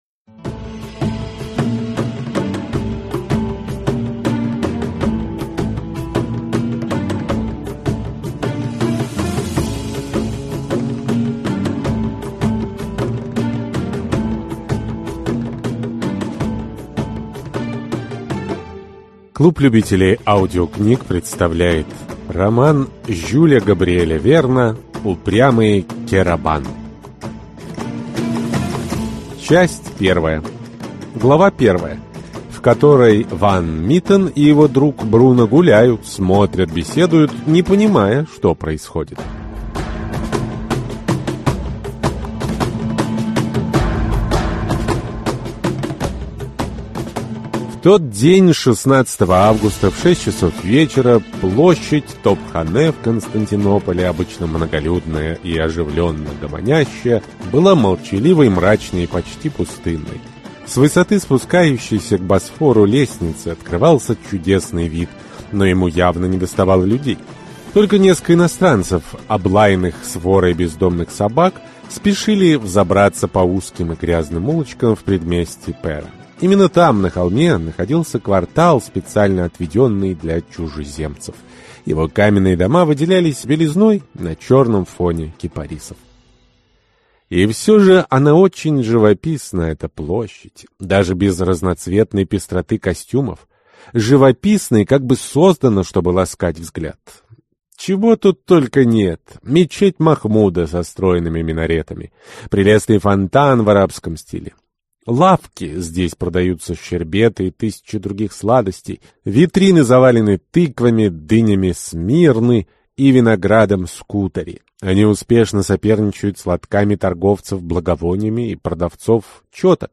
Аудиокнига Упрямец Керабан | Библиотека аудиокниг